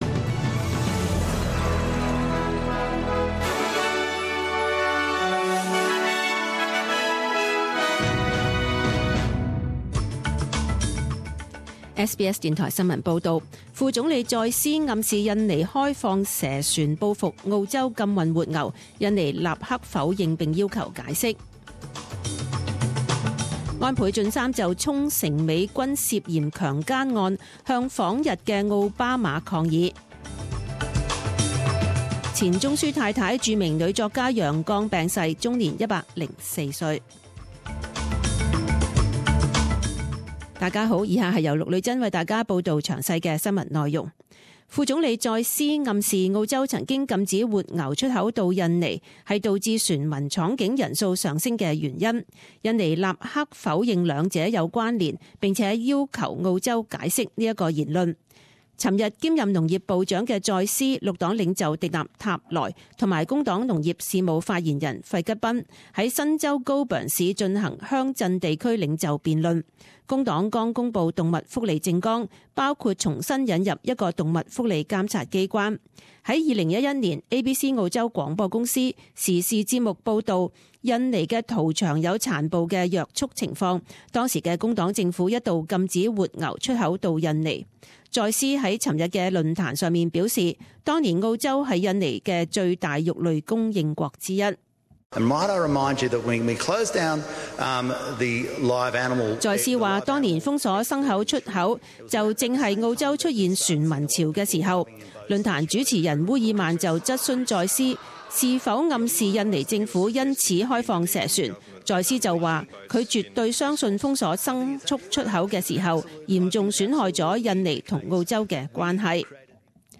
十點鐘新聞報導（五月二十六日